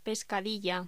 Locución: Pescadilla
voz